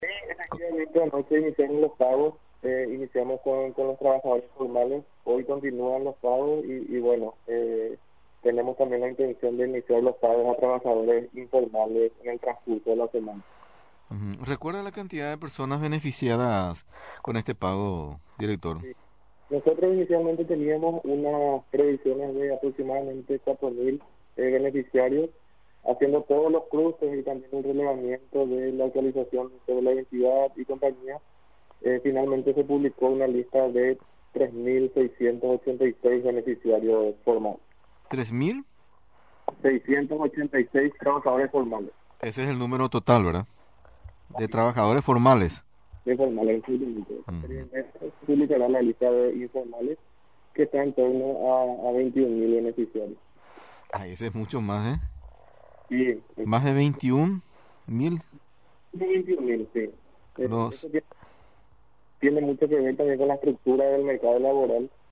Galeano detalló, en contacto con Radio Nacional, que el Gobierno desembolsará 3 millones de dólares para subsidiar a trabajadores formales e informales.